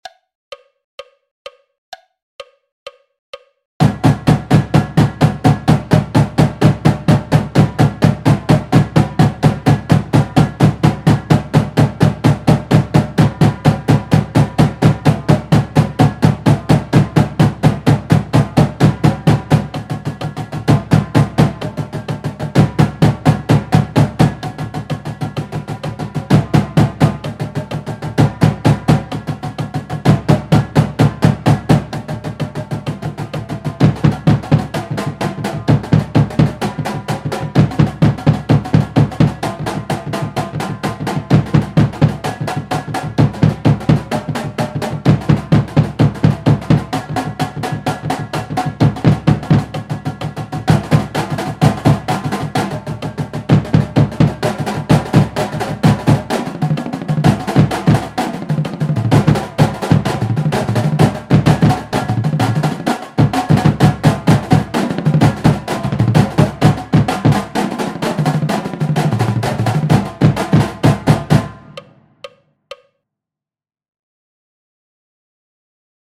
Snare Exercises
Legatos /